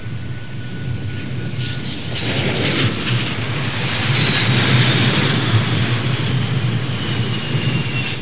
دانلود صدای طیاره 45 از ساعد نیوز با لینک مستقیم و کیفیت بالا
جلوه های صوتی
برچسب: دانلود آهنگ های افکت صوتی حمل و نقل دانلود آلبوم صدای طیاره از افکت صوتی حمل و نقل